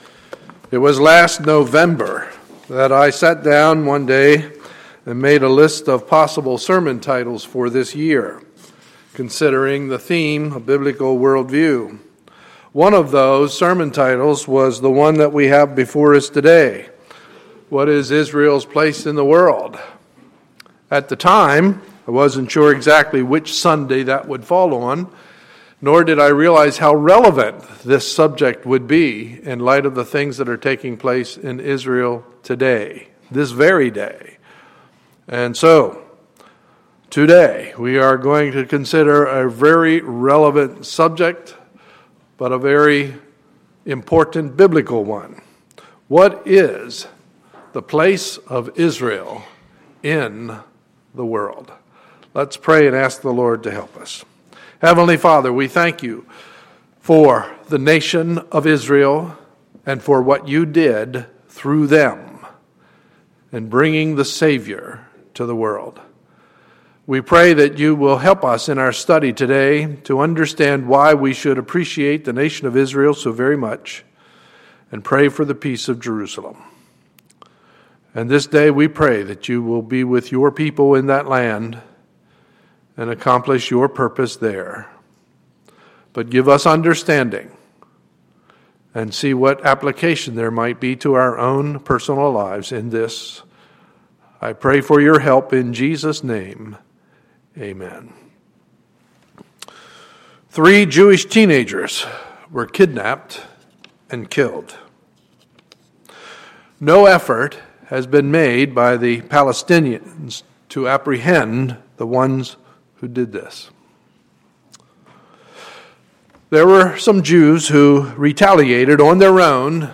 Sunday, July 13, 2014 – Morning Service